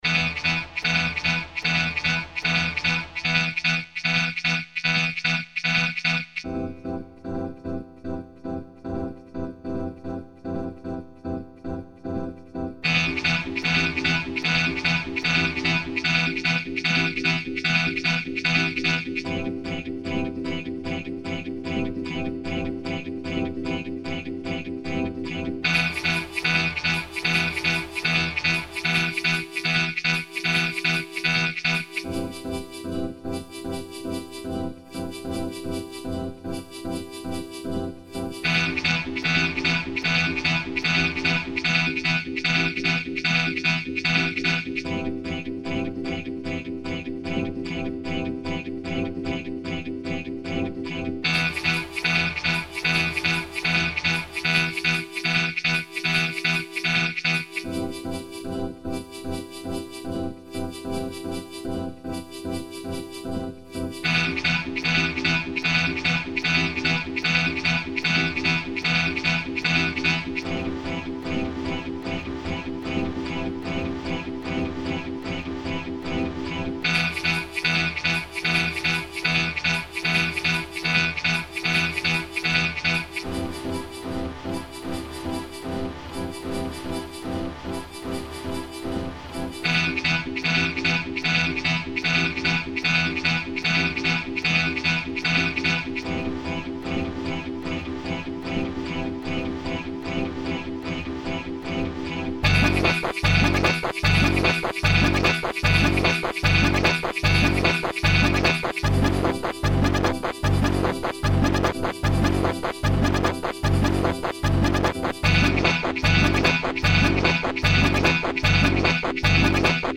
is a collection of lo-fi electronic dance tracks.
This is raw clicks'n'cuts for the new millennium.